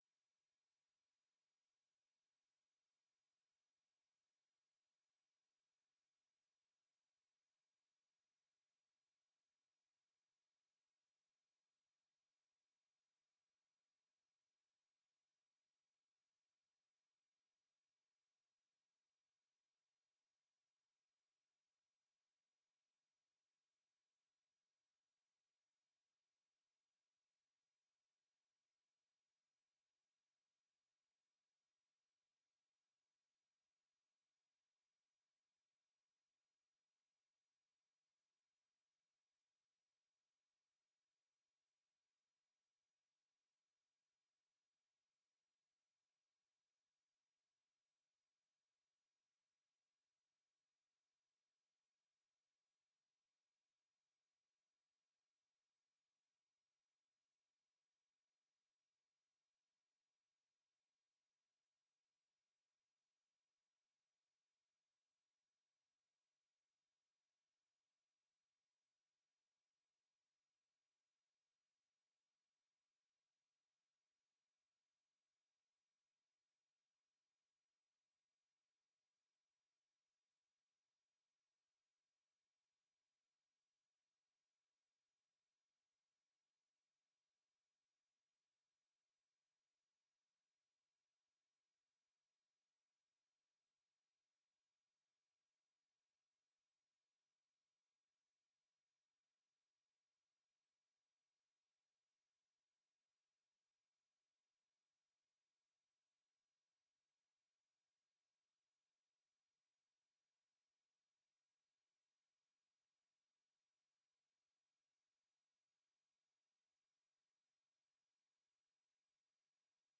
Het college werkt samen met ondernemers en onderwijs aan een visie op de economie van Doetinchem. Wethouder Hummelink geeft een toelichting op de kansen en uitdagingen van Doetinchemse economie. Daarna gaat hij in gesprek met de raad over actielijnen die de lokale en regionale economie verder kunnen versterken, in samenhang met de ambities en uitdagingen op andere onderwerpen, zoals de woningbouw, de gebiedsontwikkelingen en de arbeidsmarkt.
Locatie: Raadzaal